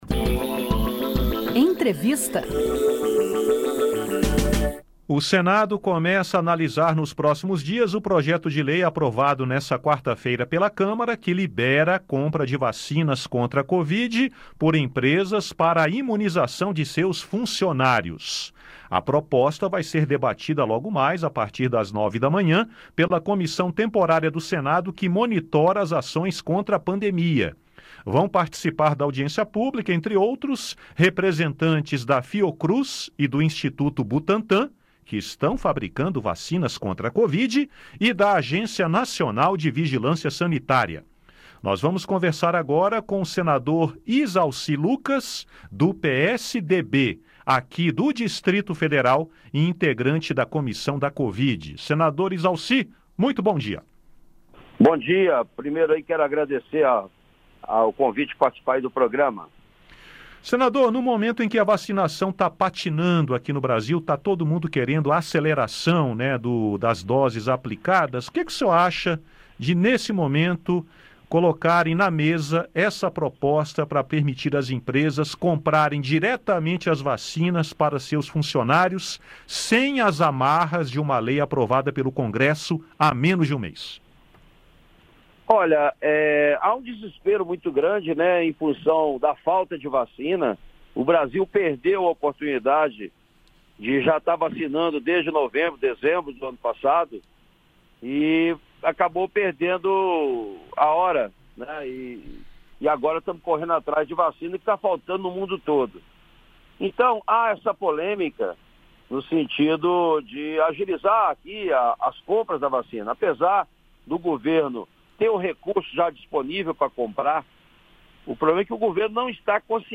Em entrevista ao Conexão Senado, o senador Izalci Lucas (PSDB-DF), integrante da comissão e presidente da Comissão Senado do Futuro, defende que a medida pode acelerar o processo de imunização no país.